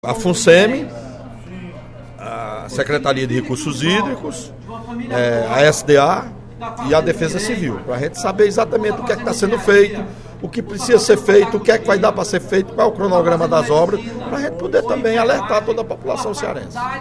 O deputado Welington Landim (Pros) afirmou, no primeiro expediente da sessão plenária desta sexta-feira (13/02), que encaminhou quatro requerimentos à Mesa Diretora solicitando a presença da Fundação Cearense de Meteorologia e Recursos Hídricos (Funceme), da Defesa Civil, da secretaria de Recursos Hídricos (SRH) e da secretaria de Desenvolvimento Agrário (SDA), para debater na Casa o enfrentamento aos efeitos da seca no Nordeste.
Dep. Welington Landim (Pros) Agência de Notícia da ALCE